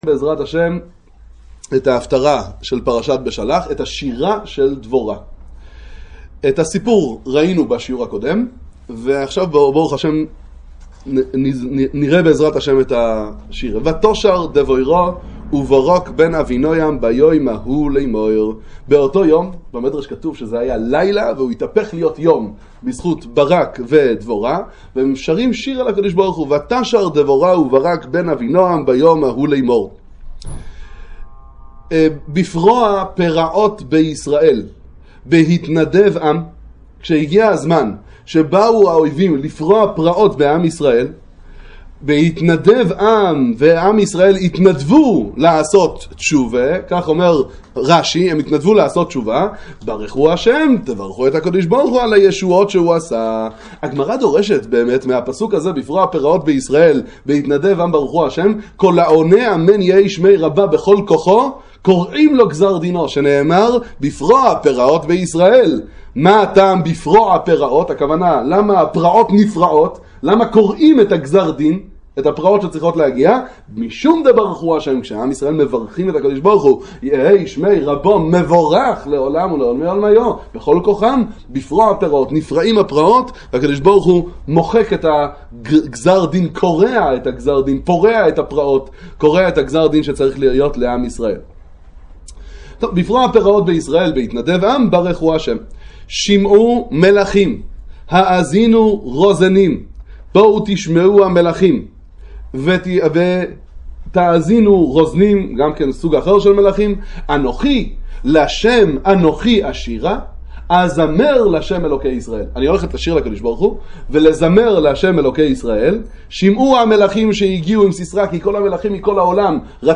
שיעור לצפיה על שירת דבורה, שיעורים בנביאים וכתובים